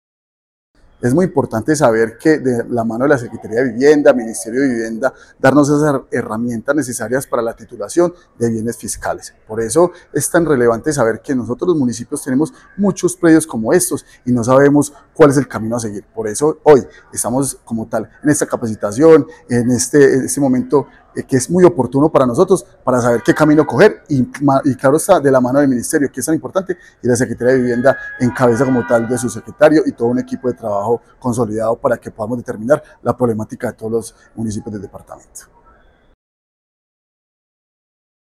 Manuel Fermín Giraldo Gutiérrez, alcalde de Salamina.